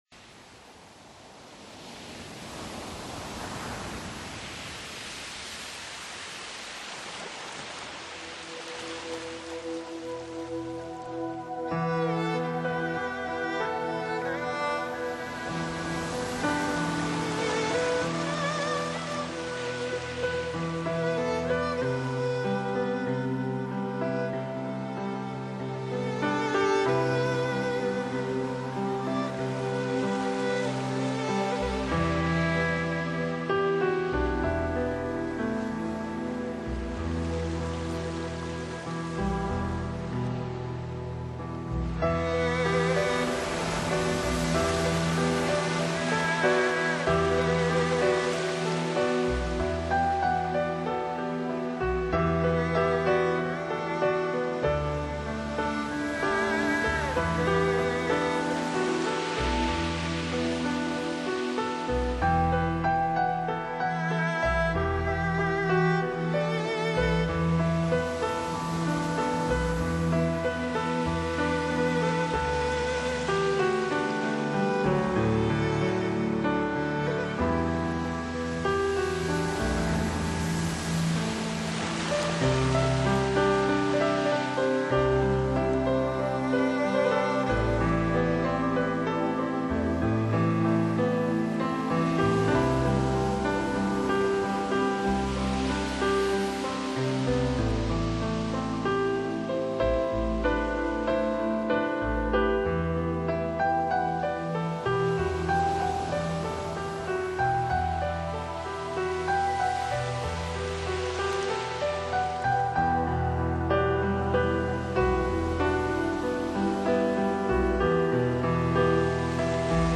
现在就请您细赏本张专辑以二胡、扬琴等来自东方的乐器和奏而出的婉约乐音，衬著自然原音，置身檀香萦绕门廊的舒压之地。